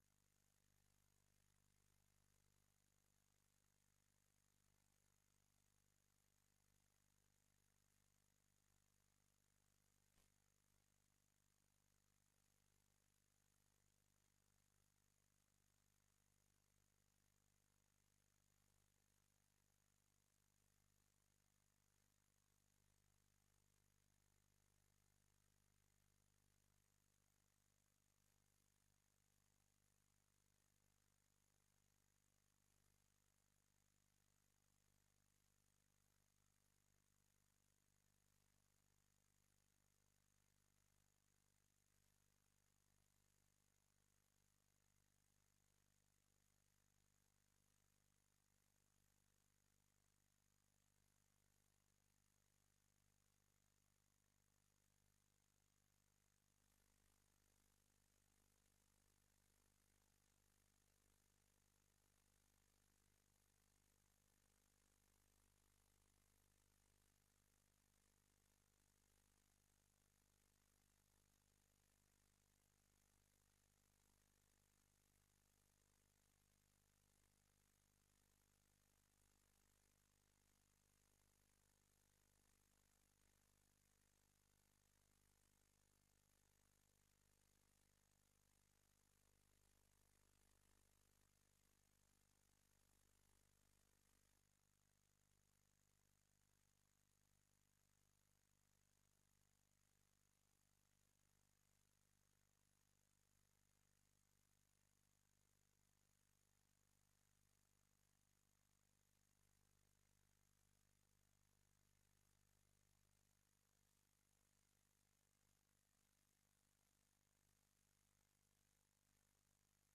Raads-info-bijeenkomst 27 juni 2024 21:00:00, Gemeente Oude IJsselstreek
Download de volledige audio van deze vergadering